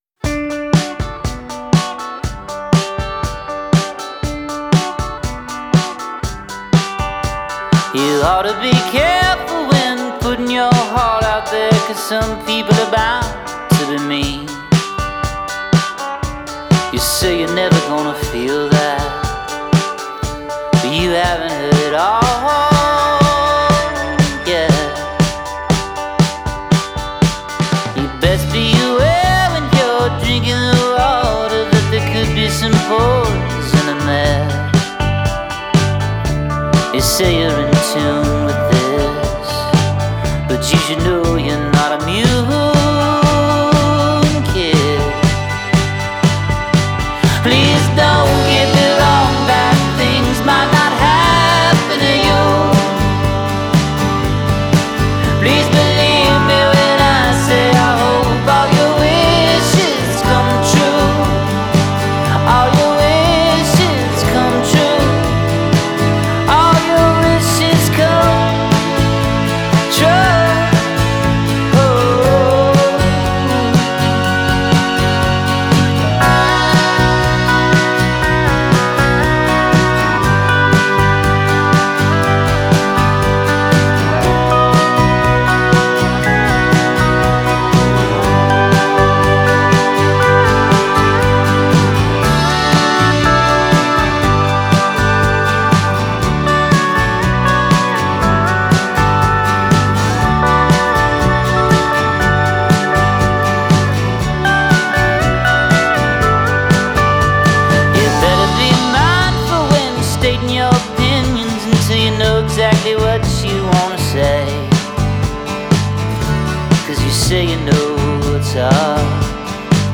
lush textures